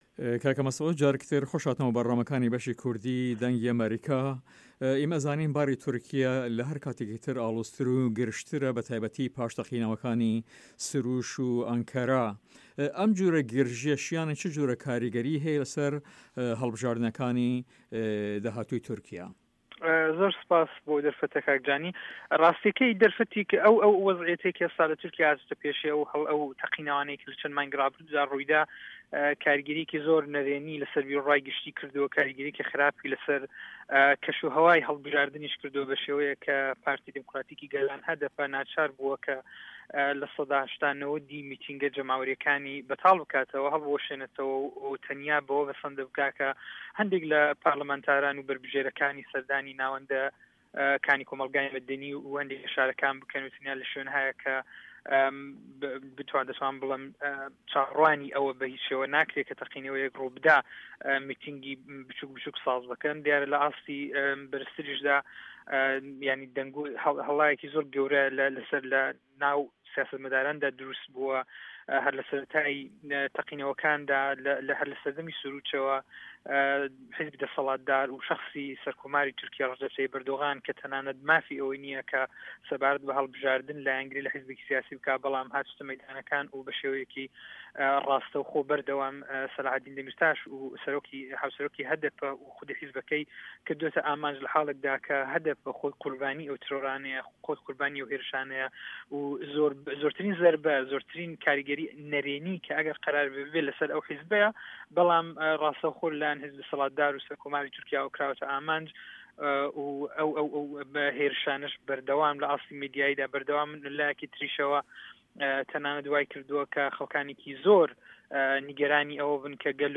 هەڤپەیڤینێکدا